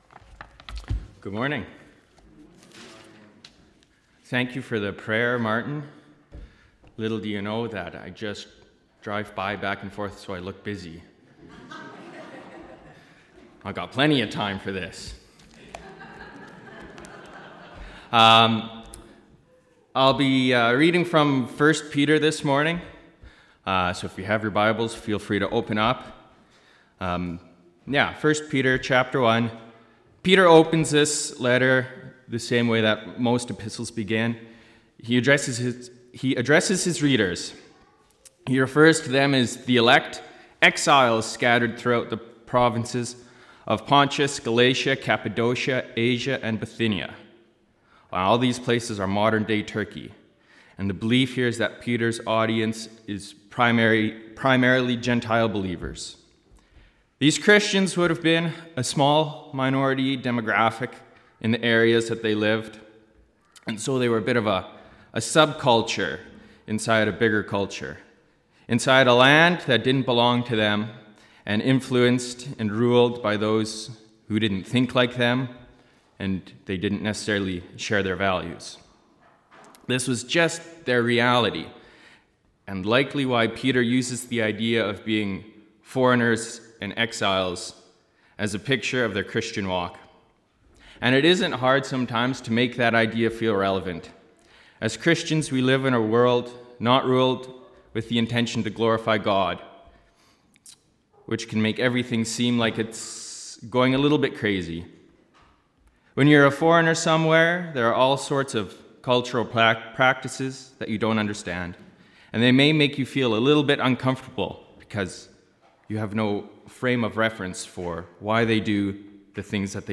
Sermons - Rosemary Mennonite Church